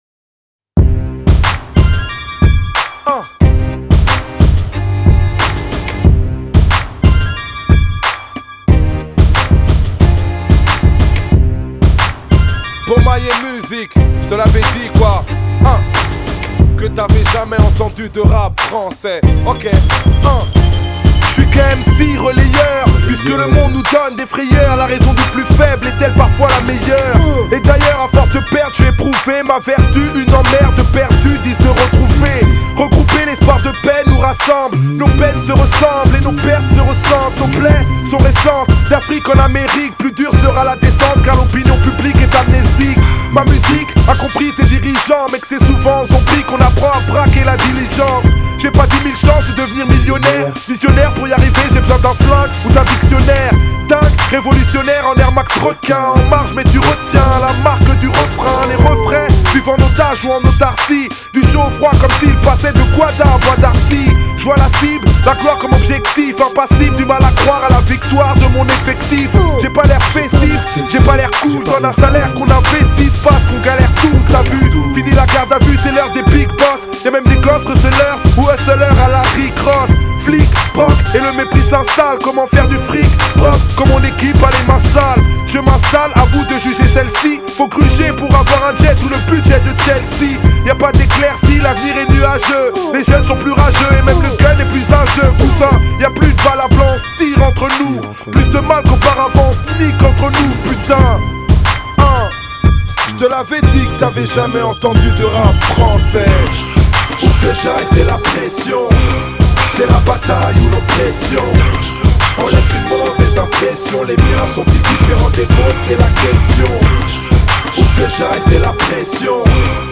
Genre: French Rap